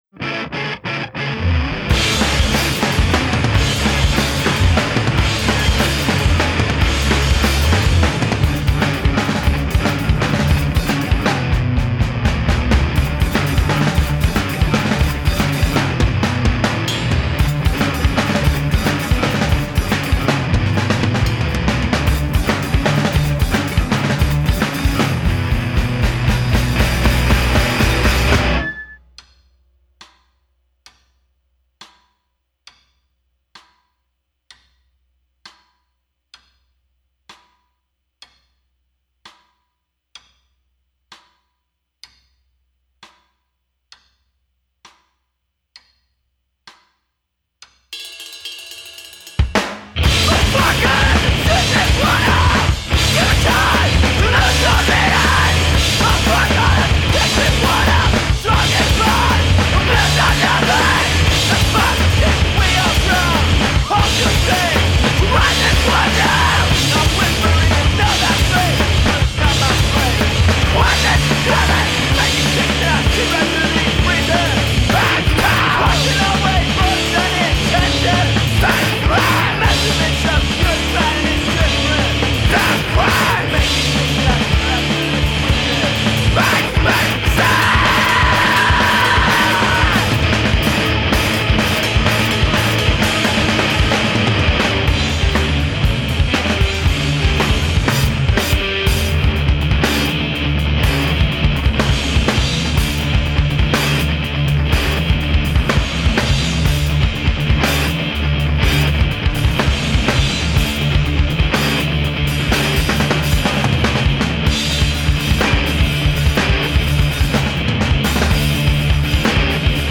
Vocals, Guitar
Drums
Bass
Hardcore